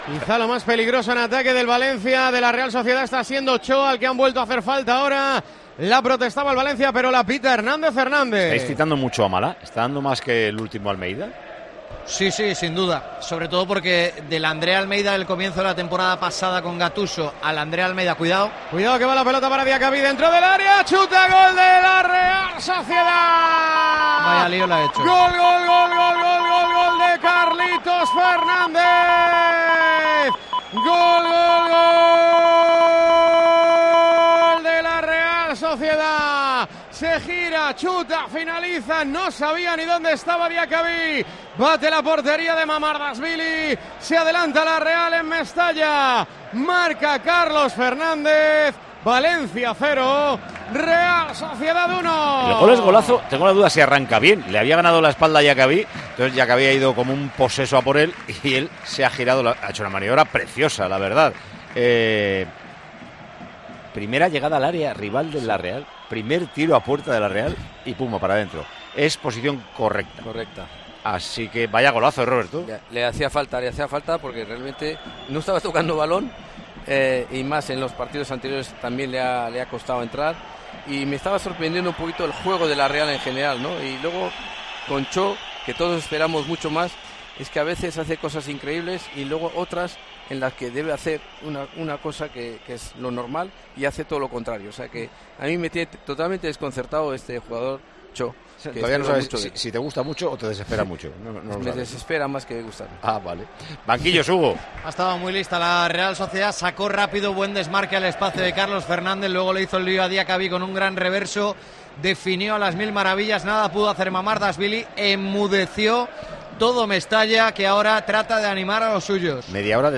ASÍ TE HEMOS CONTADO EN TIEMPO DE JUEGO LA VICTORIA DE LA REAL SOCIEDAD
Micrófono de COPE Tiempo de Juego Mestalla